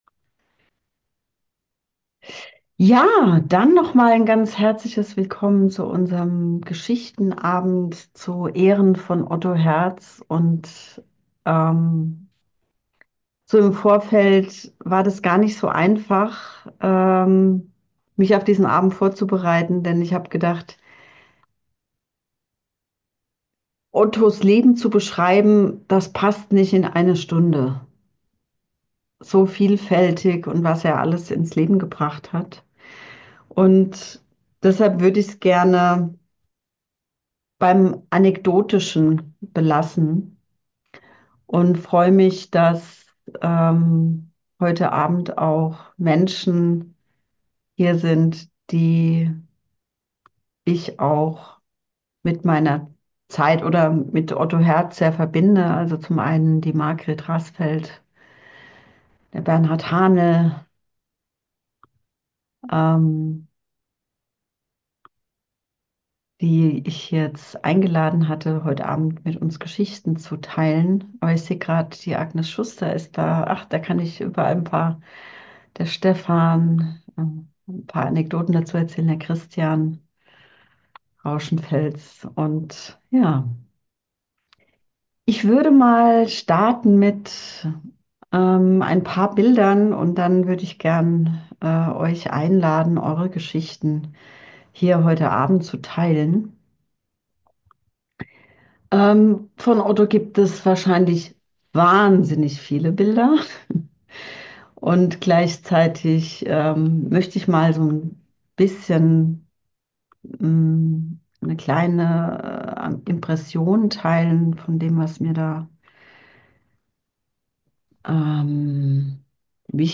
Hier kannst Du das Audio zum Gesprächsabend